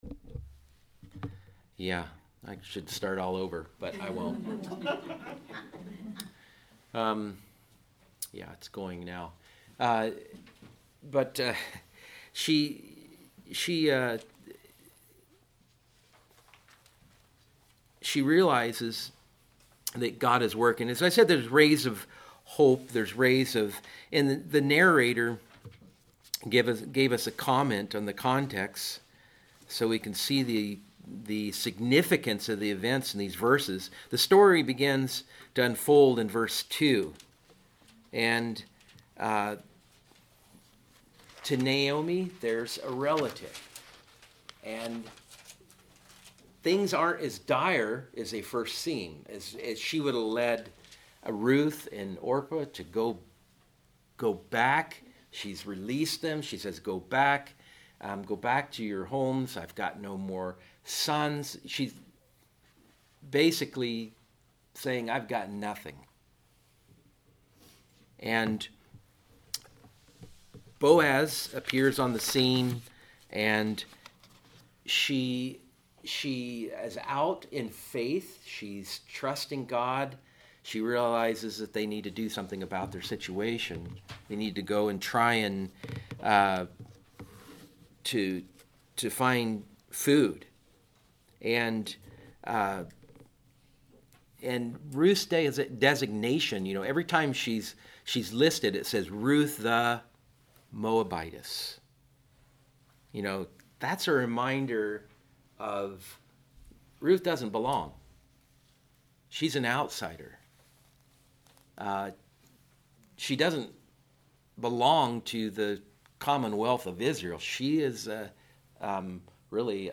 Adult Sunday School 10/5/25